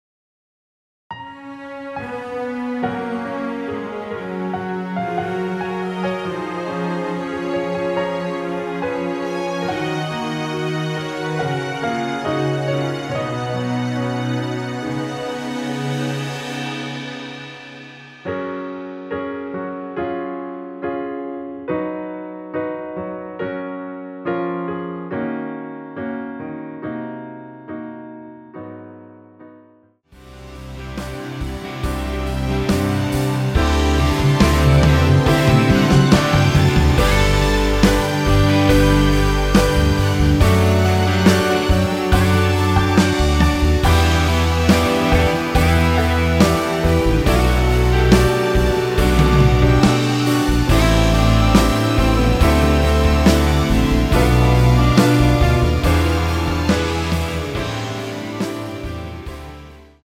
원키에서(+3)올렸으며 여성분이 부르실수 있는 MR입니다.(미리듣기 참조)
F#
앞부분30초, 뒷부분30초씩 편집해서 올려 드리고 있습니다.
중간에 음이 끈어지고 다시 나오는 이유는